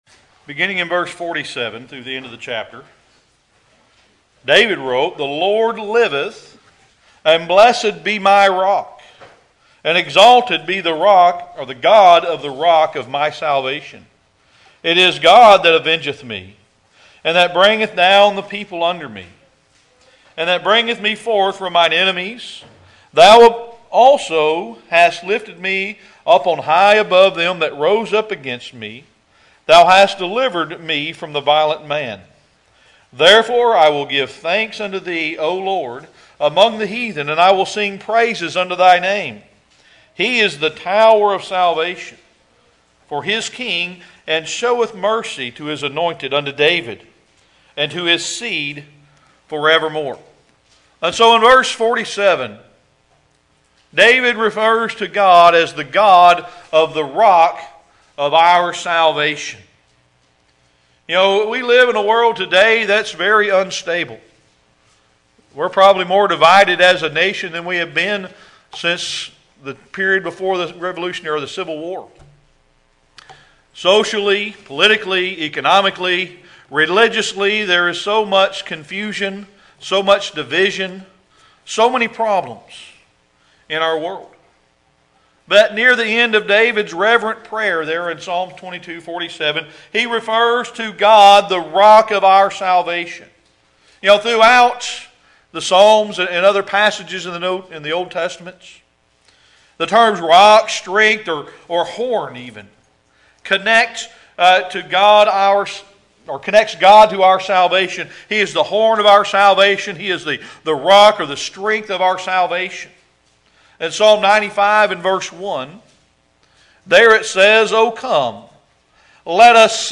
Series: Sermon Archives
Acts 4:11-12 Service Type: Sunday Evening Worship Introduction